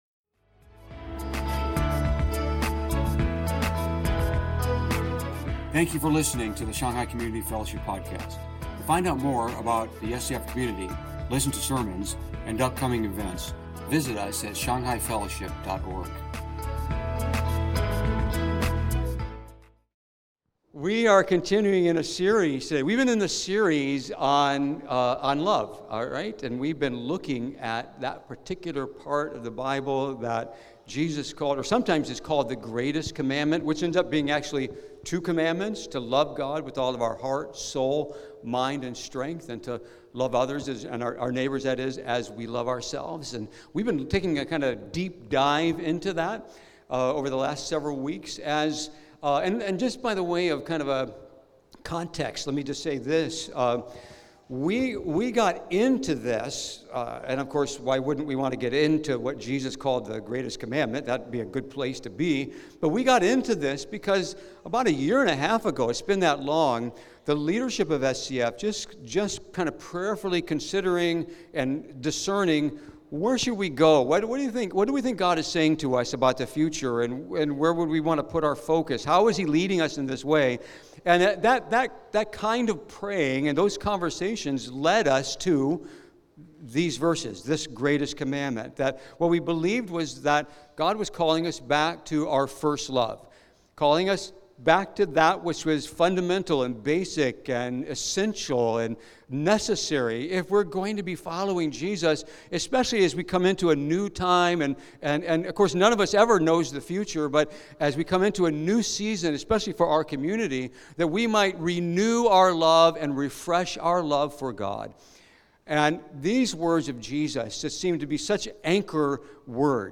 From Series: "Stand-Alone Sermon"